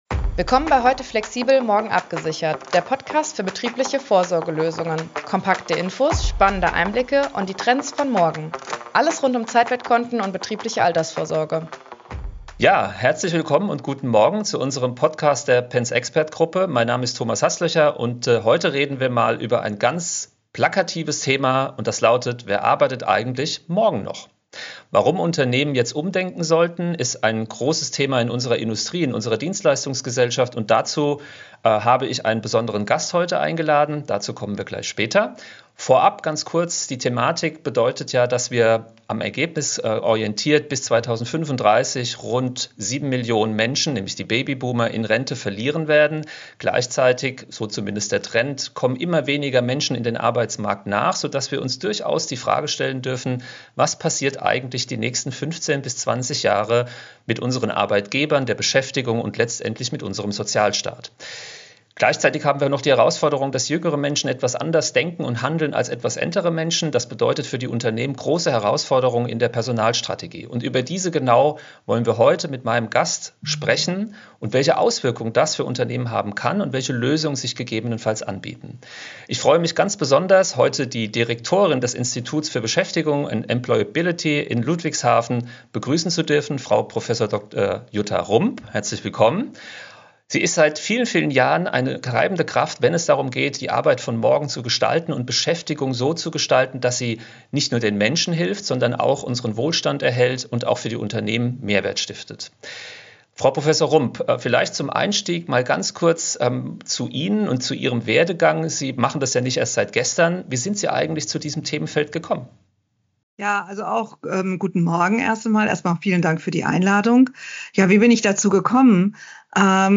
Ein Gespräch voller Impulse für Unternehmen, die den demografischen Wandel aktiv gestalten und als attraktive Arbeitgeber sichtbar bleiben wollen.